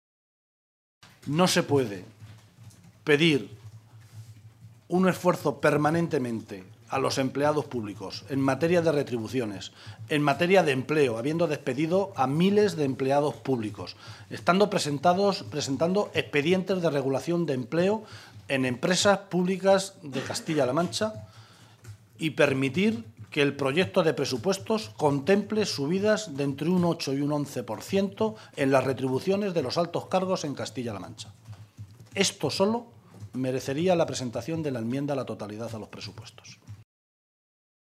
José Luis Martínez Guijarro, portavoz del Grupo Parlamentario Socialista
Cortes de audio de la rueda de prensa